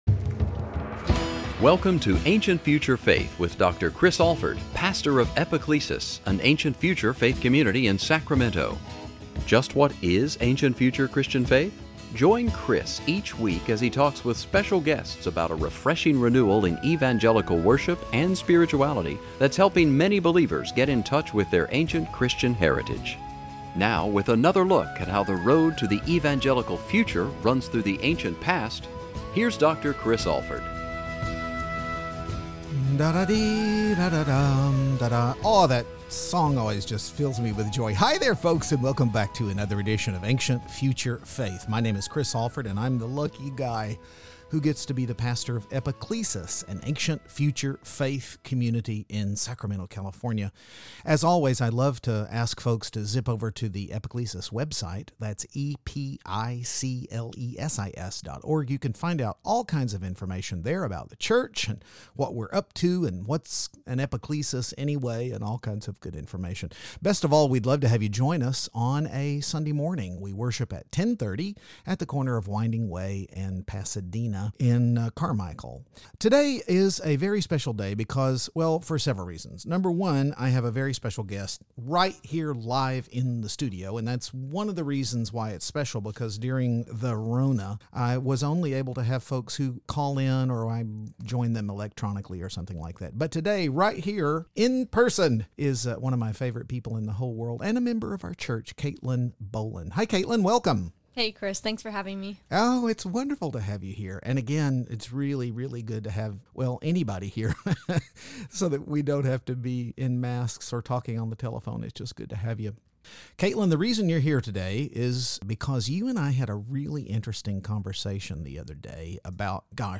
But along the way, we also touched on what it's like to be a Christian in college today, holding a biblical worldview in a secular society, and what university students need most from their church families. ...An interview that will both encourage and challenge you, but also give you hope.